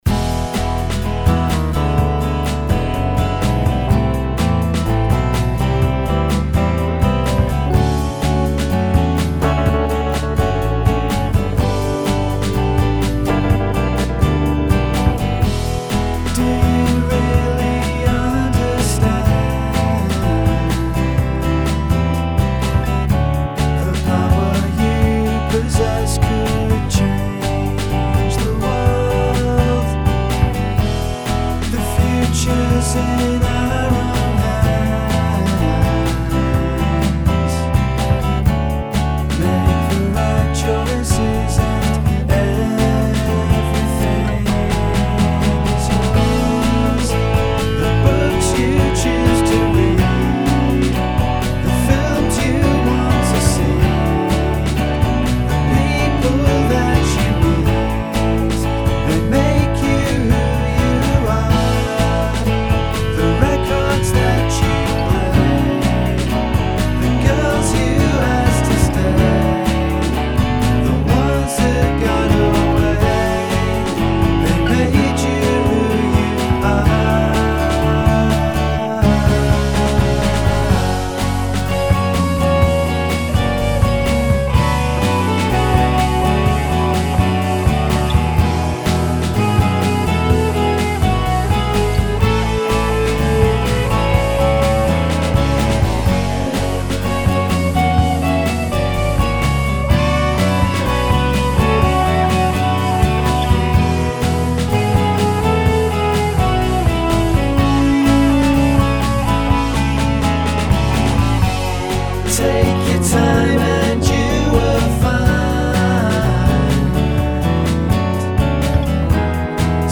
* Demo *